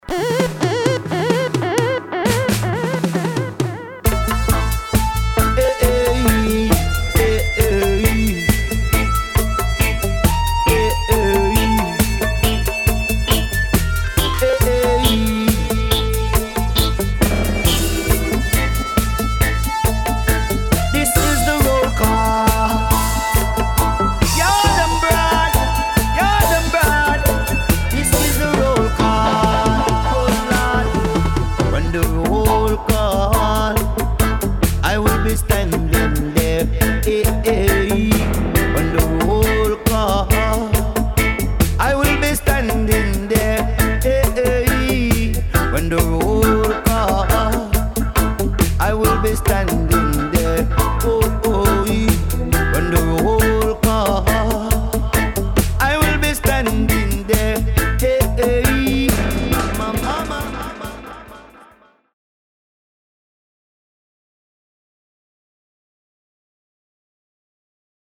12″ Showcase with Vocals Dubs & Versions.
modern roots steppers
All recorded, mixed & mastered